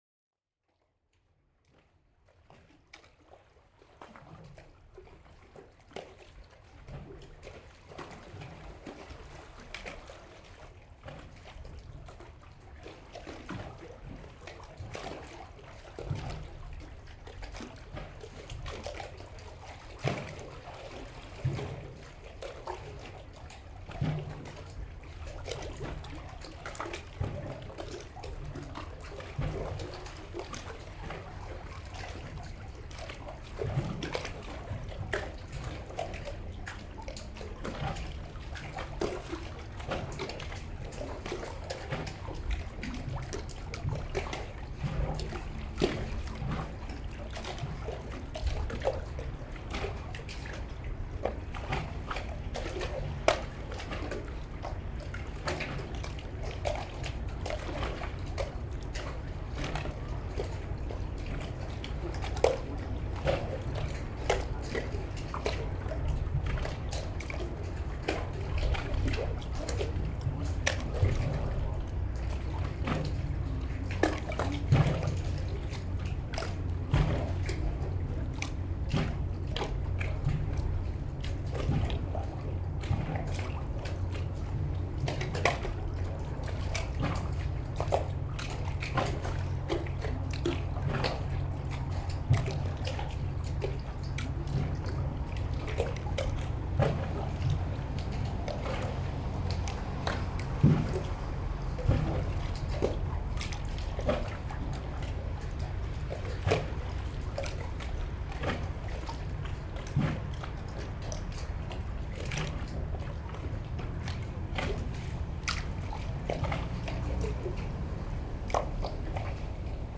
Westerkade, october 09
For the recording I used a directional microphone, a contact microphone, an ultrasonic detector (bats) and a binaural microphone.